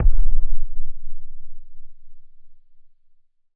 explosion_far_distant_08.wav